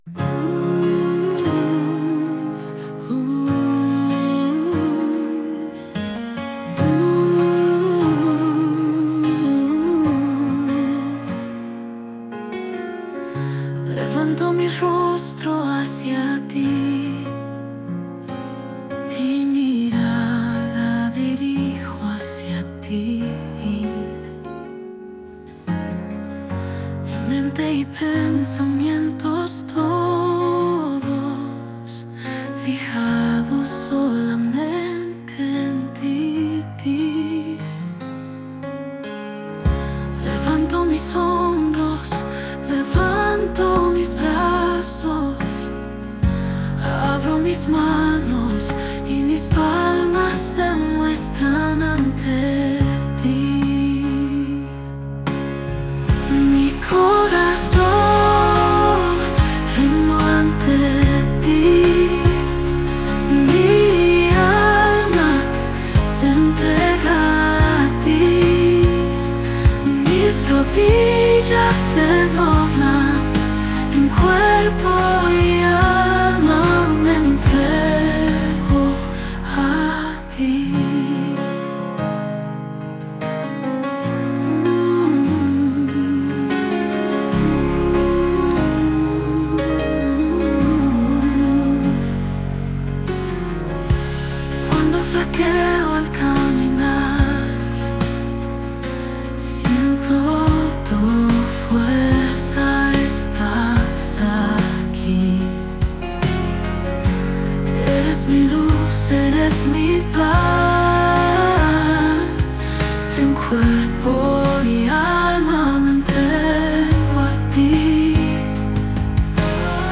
género Rock.
Rock Suave